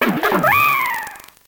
Cri de Darumarond dans Pokémon Noir et Blanc.